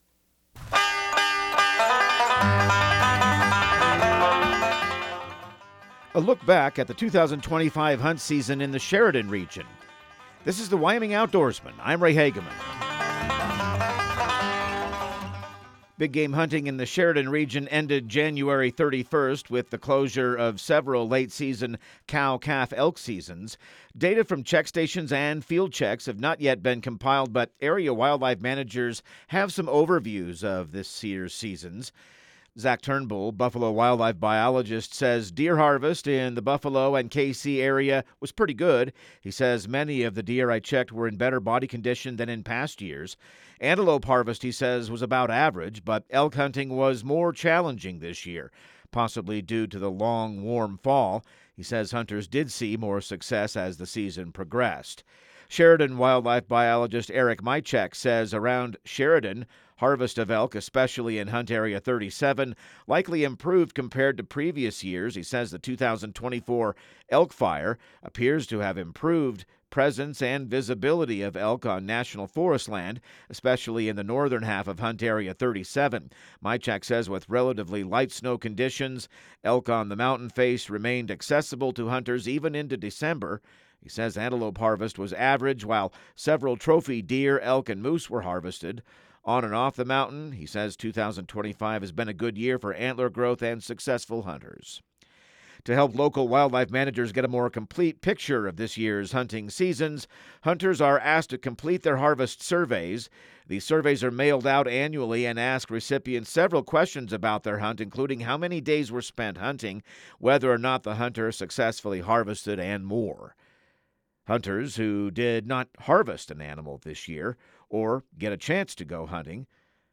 Radio news | Week of February 2